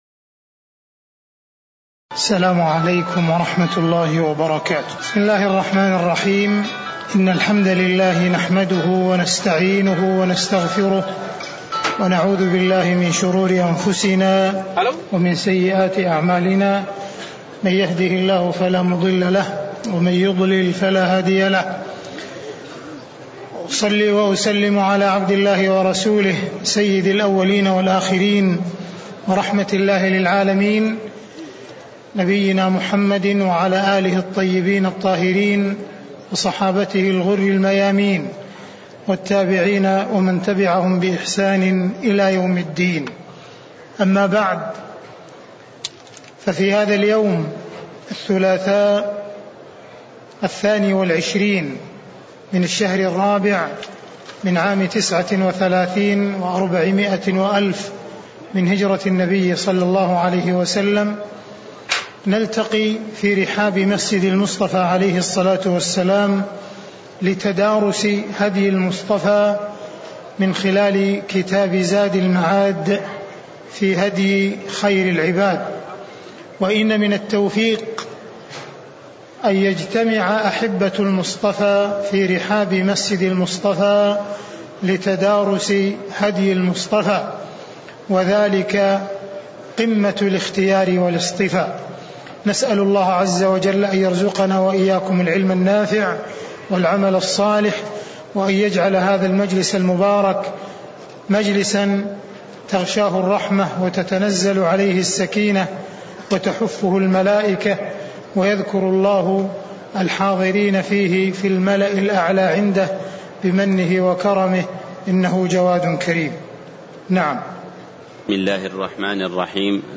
المكان: المسجد النبوي الشيخ: معالي الشيخ أ.د. عبدالرحمن بن عبدالعزيز السديس